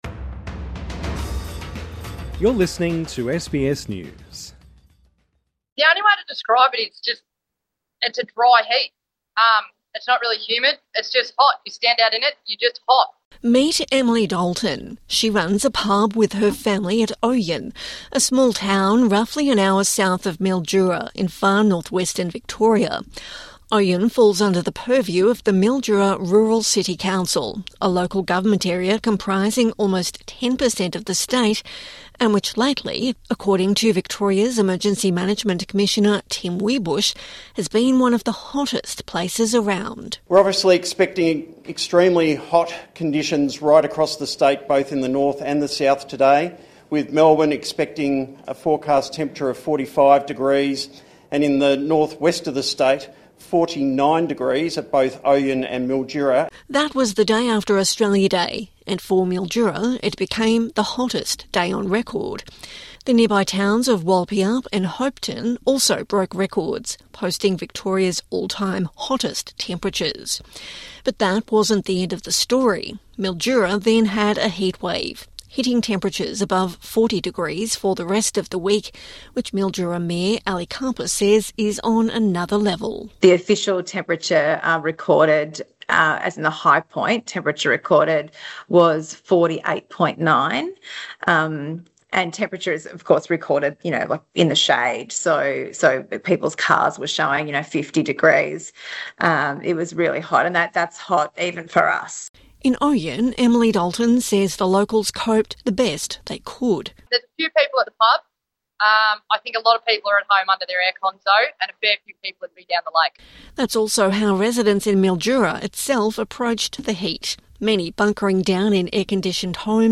Experts say these kinds of phenomena are going to intensify over time because of the impacts of climate change - but while there has been plenty of coverage of how that impacts on urban centres, regional Australia has not received the same attention. SBS visited Mildura to see how the region felt the heat, and what they're doing to cope with climate change at a local level.